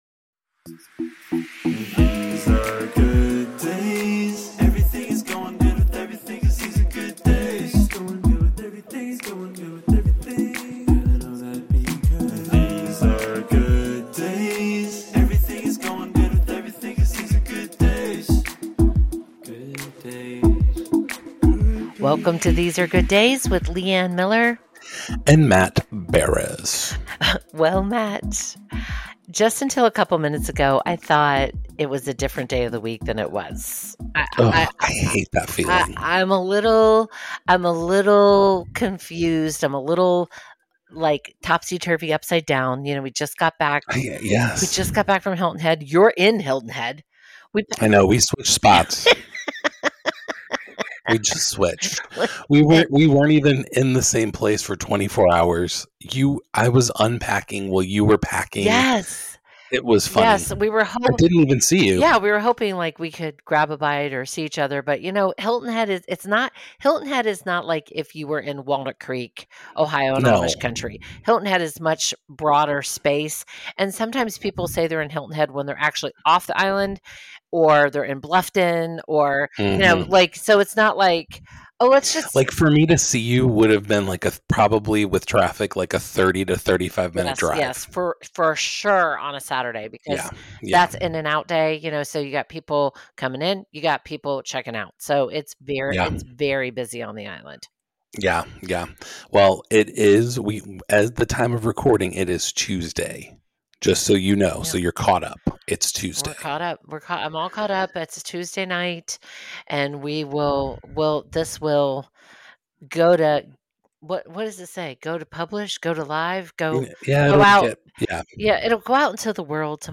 Join us in this conversation all about fighting the funk and getting on the other side.